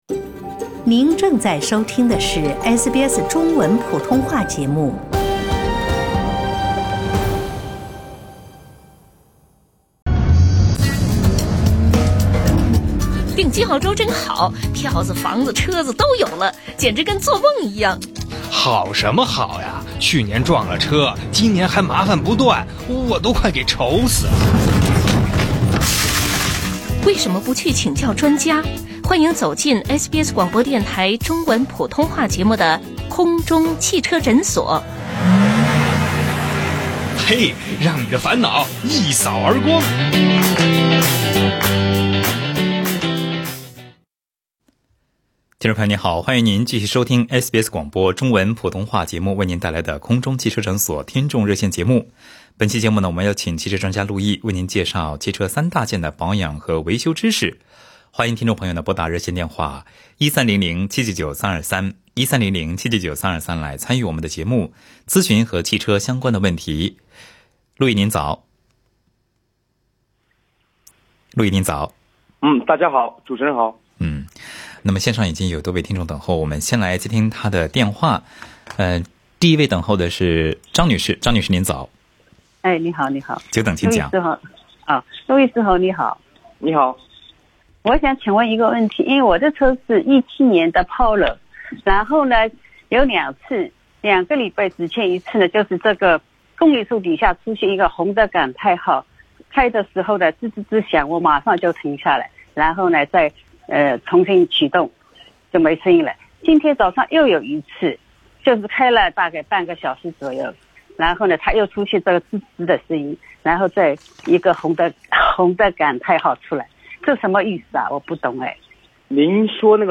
在本期SBS中文普通话节目《空中汽车诊所》听众热线节目中